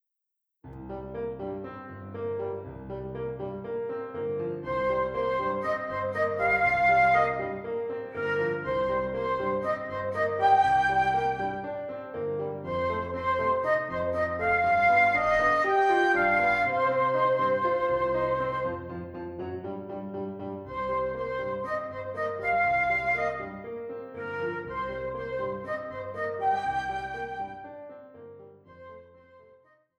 with Piano or backing tracks
Flute and Piano